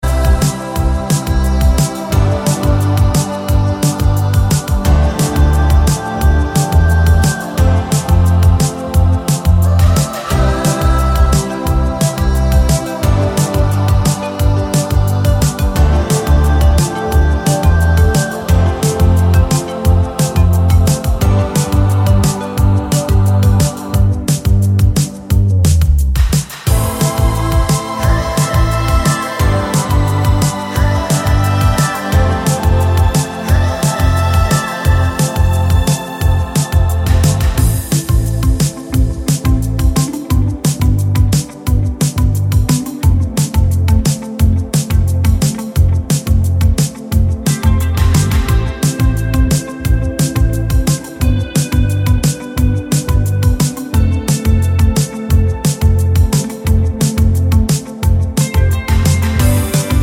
no Backing Vocals at all Pop (2020s) 3:55 Buy £1.50